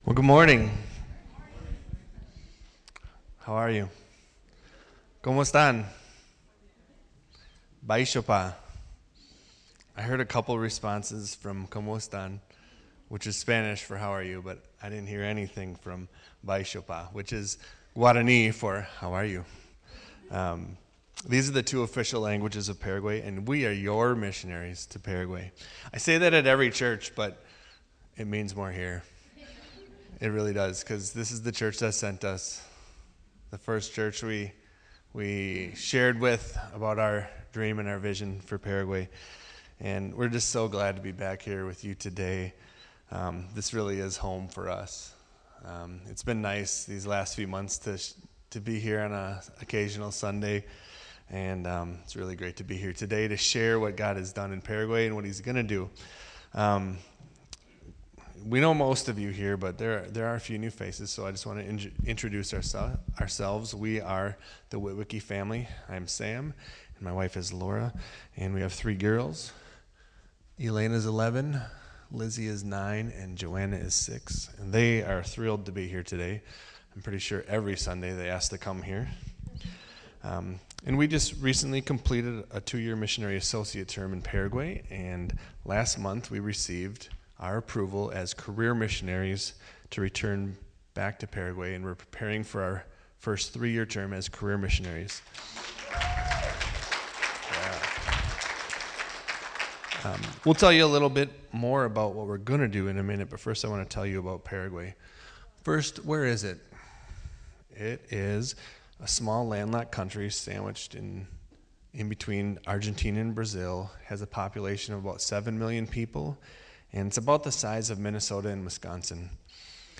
April 30, 2023 Poverty Mindset – Missionaries Preacher: Missionary Speaker Passage: Isaiah 58:6-10 What is a poverty mindset? Does it come only when we don’t have material or physical things?